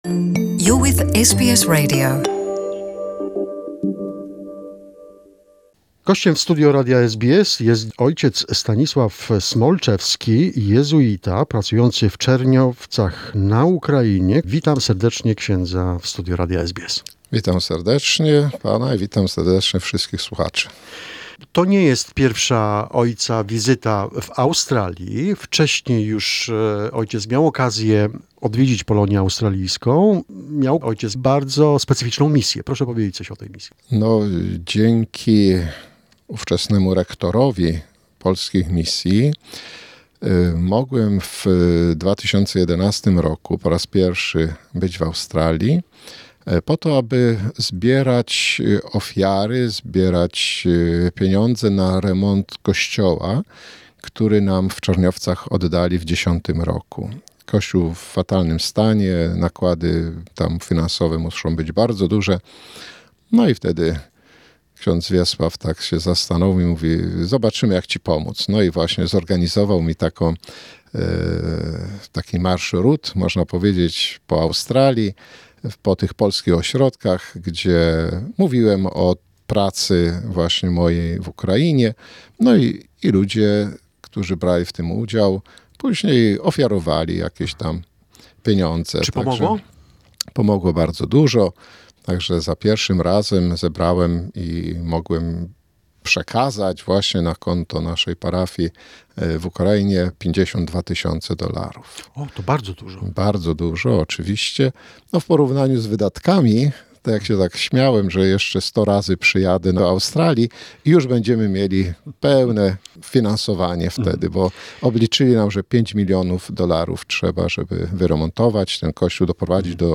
talks about how difficult is to restore and renovate a historic church ....This is a part one of the interview.